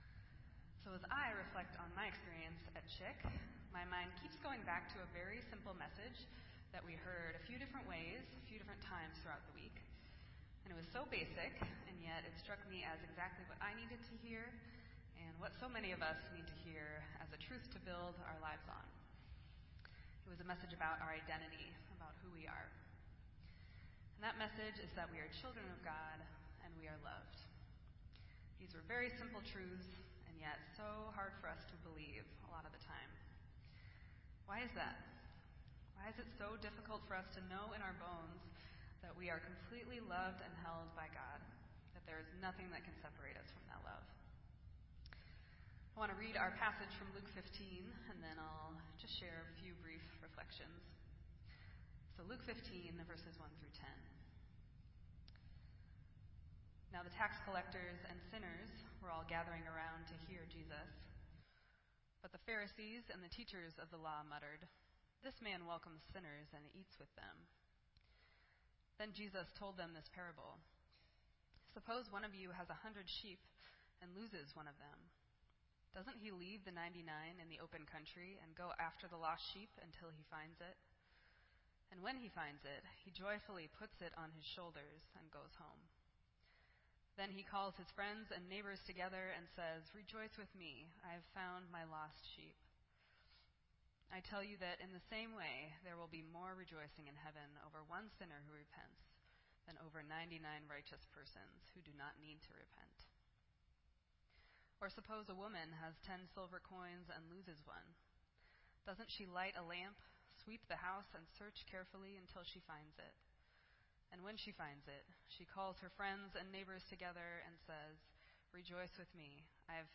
This entry was posted in Sermon Audio on August 13